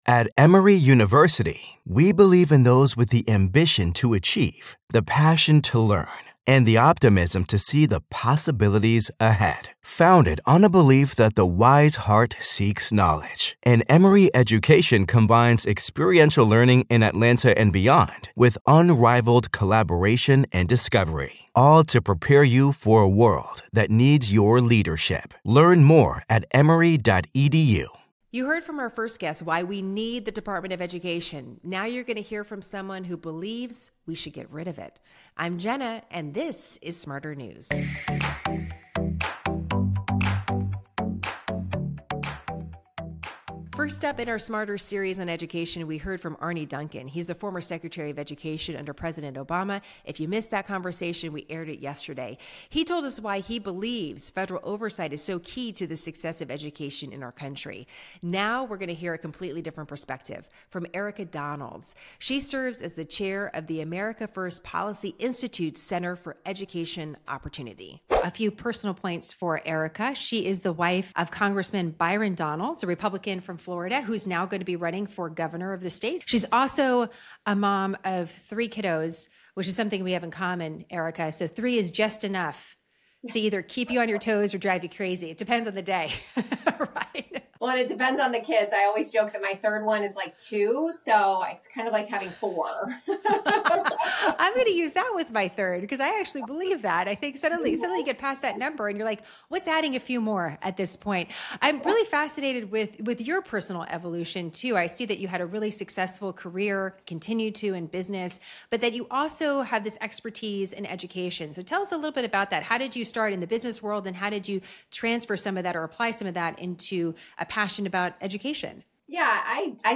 SmartHERNews Jenna Lee Education, News 5 • 615 Ratings 🗓 16 April 2025 ⏱ 25 minutes 🔗 Recording | iTunes | RSS 🧾 Download transcript Summary The second of two interviews with different perspectives on the same hot topic: education in America. Should we keep or quash the Department of Education?